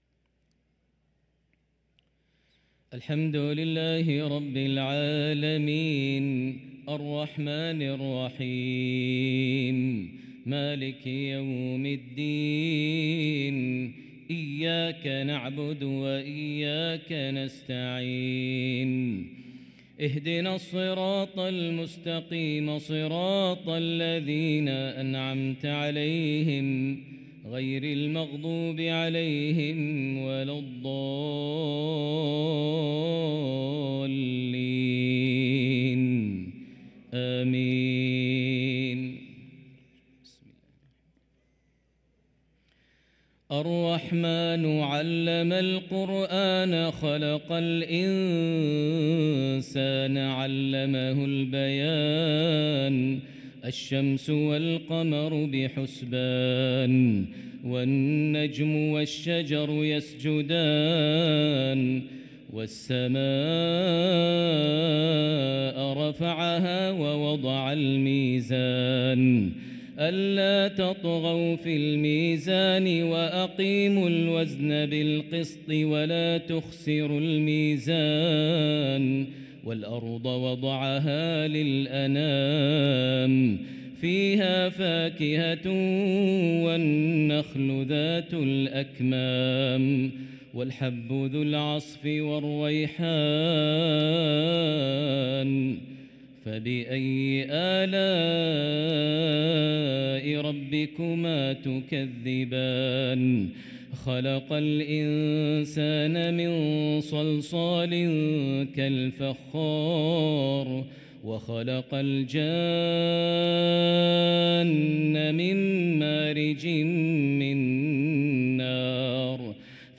صلاة العشاء للقارئ ماهر المعيقلي 22 ربيع الأول 1445 هـ